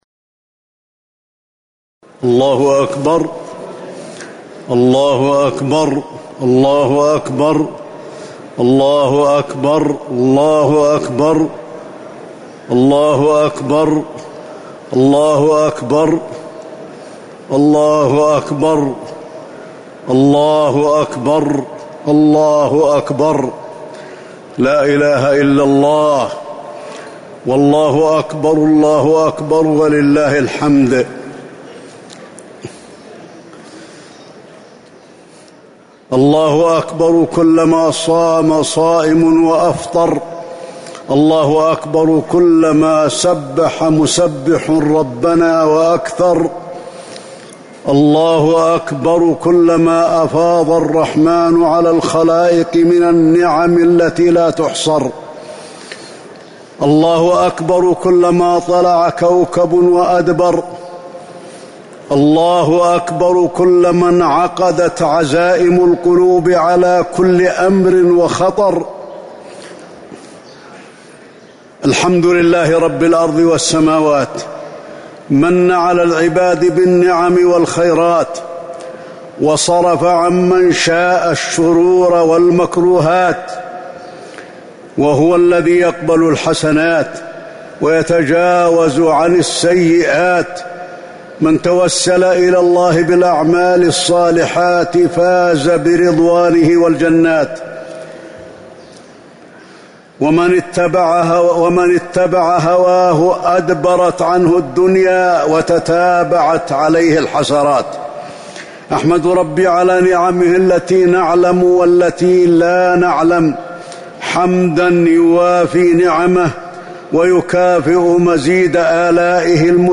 خطبة عيد الفطر- المدينة - الشيخ علي الحذيفي
تاريخ النشر ١ شوال ١٤٤٣ هـ المكان: المسجد النبوي الشيخ: فضيلة الشيخ د. علي بن عبدالرحمن الحذيفي فضيلة الشيخ د. علي بن عبدالرحمن الحذيفي خطبة عيد الفطر- المدينة - الشيخ علي الحذيفي